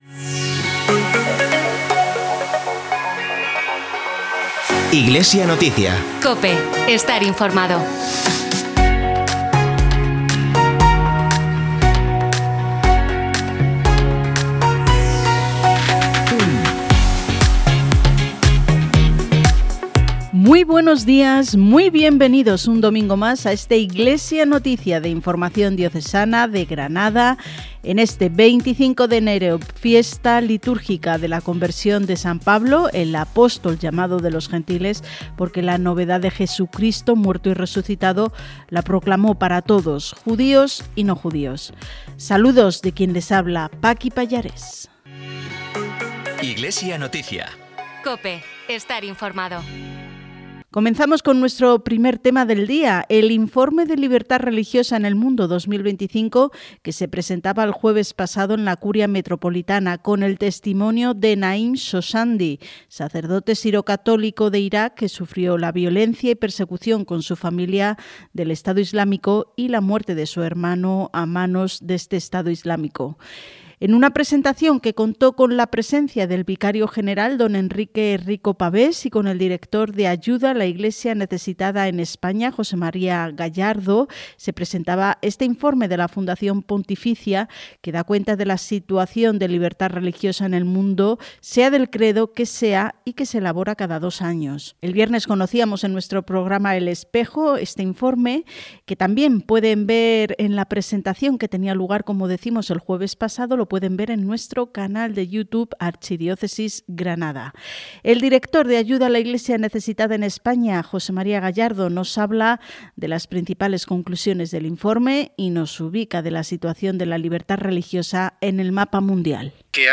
Informativo diocesano en COPE Granada el 25 de enero de 2026 - Archidiócesis de Granada
En el informativo diocesano “Iglesia Noticia”, emitido el domingo 25 de enero de 2026, en COPE Granada y COPE Motril, dedicamos el espacio al Informe de Libertad Religiosa en el Mundo que se presentó recientemente en la Archidiócesis de Granada, con Ayuda a la Iglesia Necesitada, que realiza este Informe cada dos años.